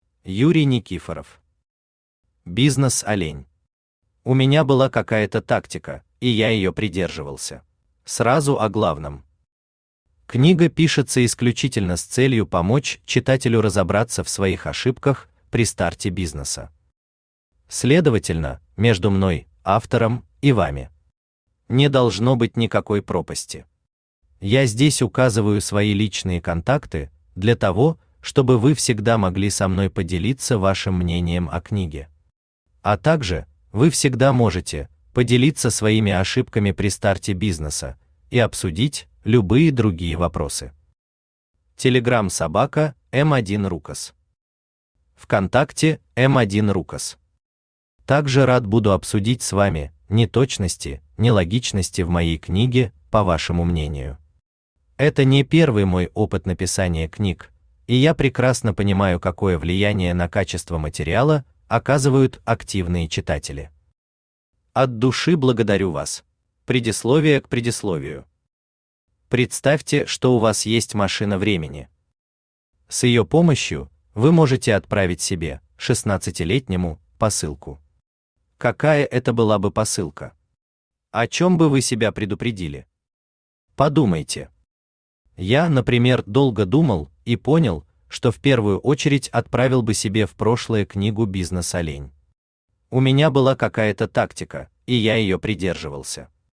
У меня была какая-то тактика, и я ее придерживался Автор Юрий Никифоров Читает аудиокнигу Авточтец ЛитРес.